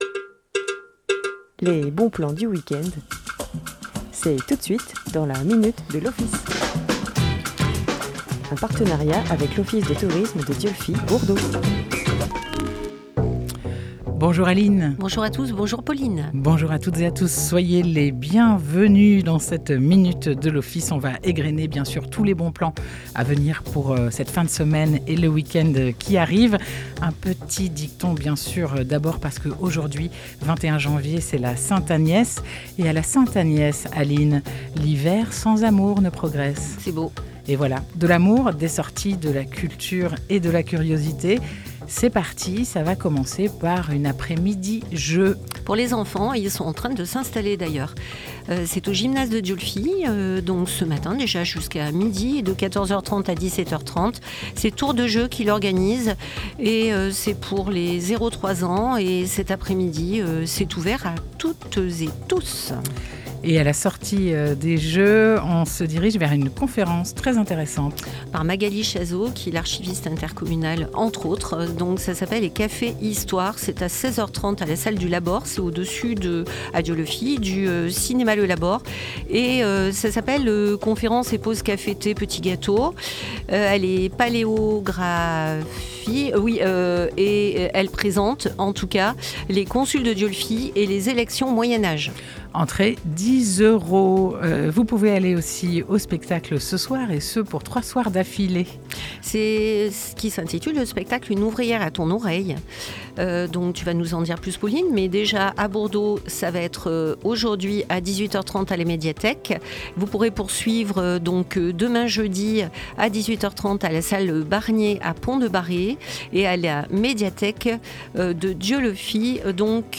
Chaque mercredi à 9h30 en direct